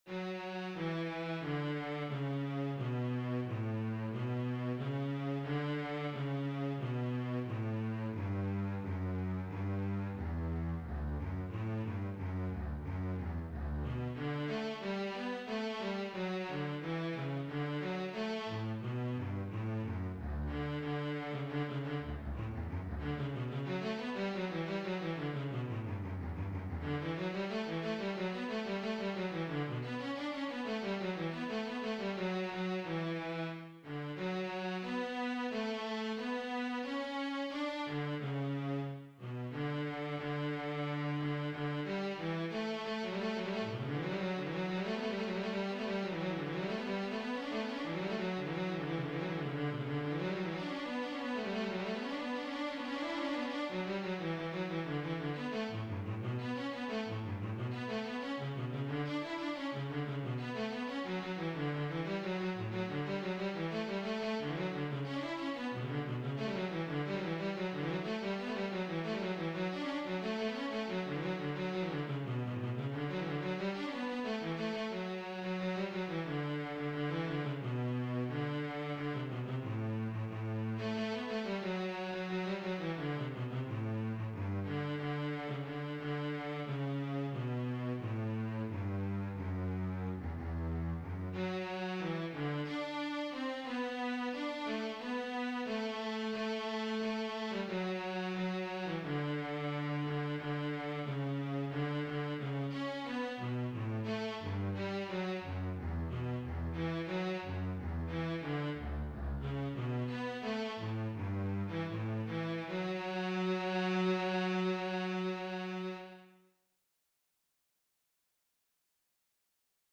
DIGITAL SHEET MUSIC - CELLO SOLO